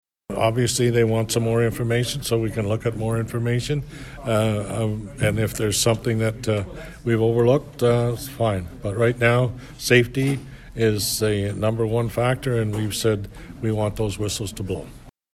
Mayor Jim Harrison agreed that safety is the top priority.